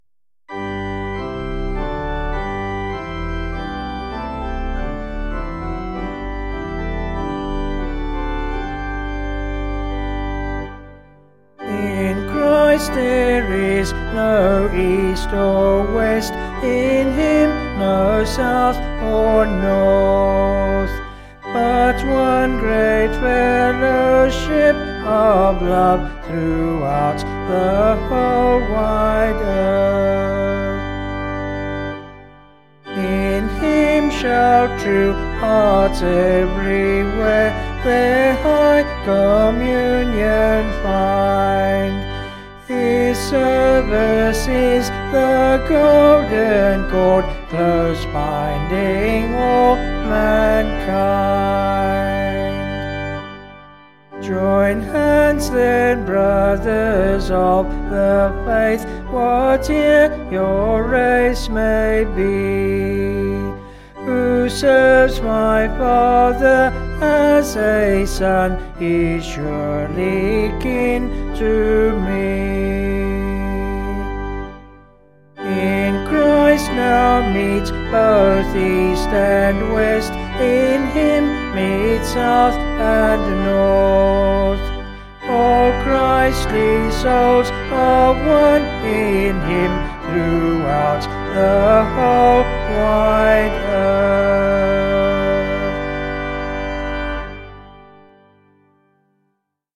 Vocals and Organ   264kb Sung Lyrics